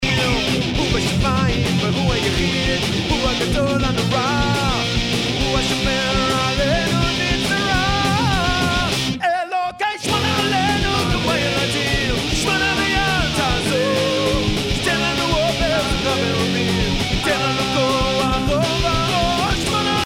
they keep things fast, loud and furious.